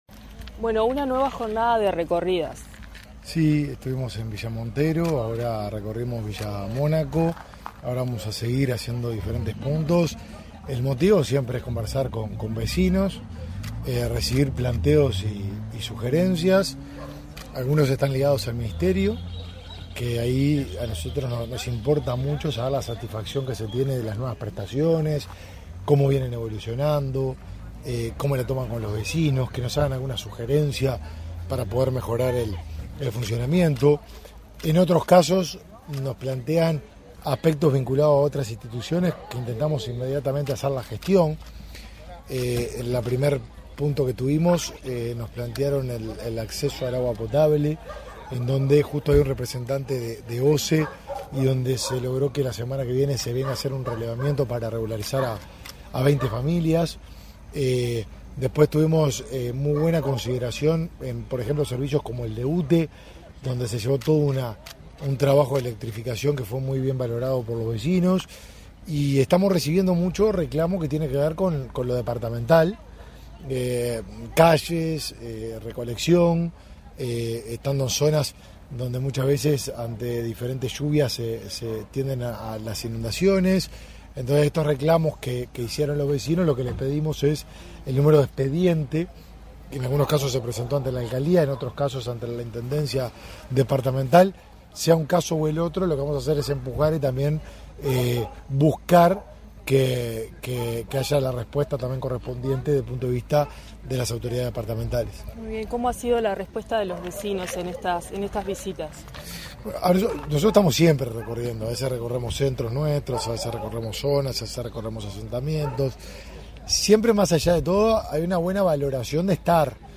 Entrevista al titular del Mides, Martín Lema
Entrevista al titular del Mides, Martín Lema 31/01/2024 Compartir Facebook X Copiar enlace WhatsApp LinkedIn El titular del Ministerio de Desarrollo Social (Mides), Martín Lema, visitó, este 31 de enero, el departamento de Canelones, para interiorizarse acerca de las prestaciones de esa cartera en esa zona. En la oportunidad, dialogó con Comunicación Presidencial.